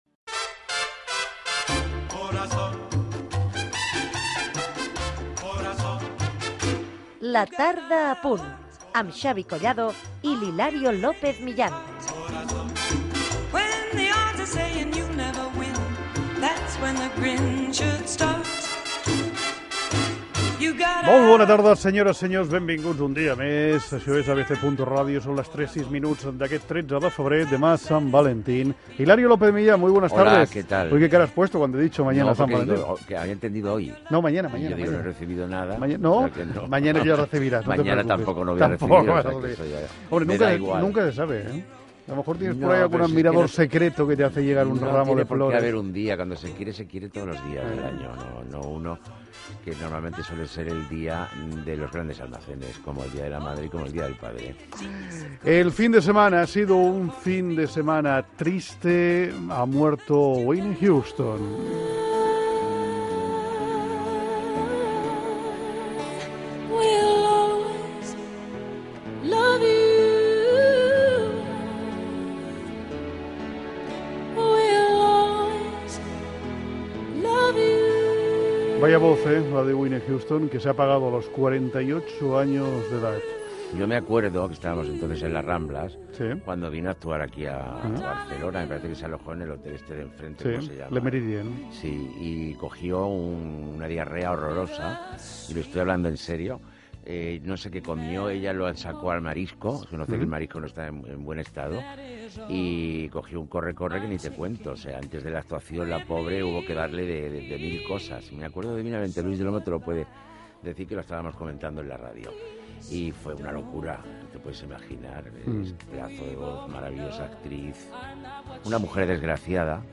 Careta del programa, demà és Sant Valentí, la mort de la cantant Whitney Houston, entrevista als integrants del grup musical Don Jhonsons i tema musical tocat a l'estudi
Entreteniment